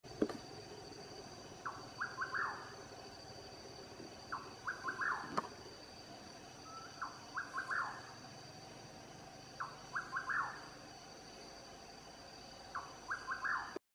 Atajacaminos Colorado (Antrostomus rufus)
Nombre en inglés: Rufous Nightjar
Fase de la vida: Adulto
Localidad o área protegida: Parque Nacional Calilegua
Condición: Silvestre
Certeza: Vocalización Grabada